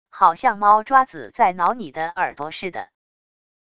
好像猫抓子在挠你的耳朵似的wav音频